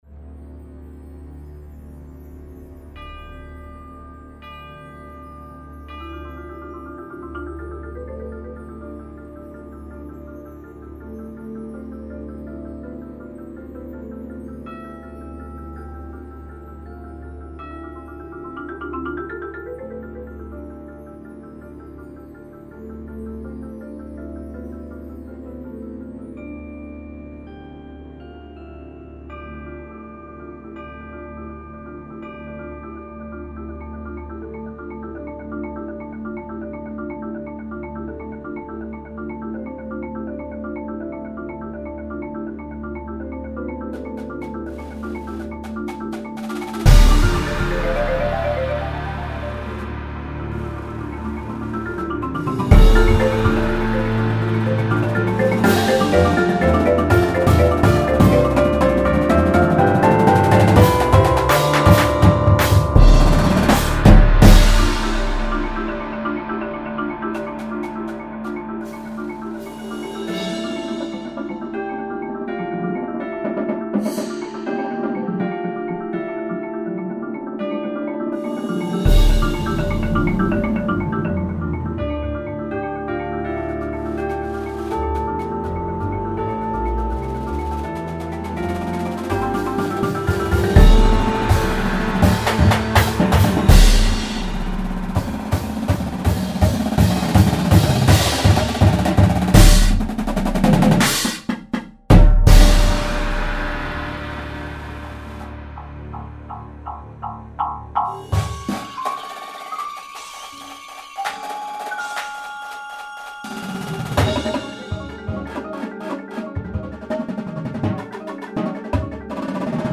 The tag represents a show of solidarity, love, and courage.
Snares
Tenors (Sixes)
Bass Drums (5)
Cymbals
Marimba 1, 2, 3
Xylophone/Chimes
Vibraphone 1, 2
Bass Guitar
Synth 1, 2
Auxiliary Percussion 1, 2, 3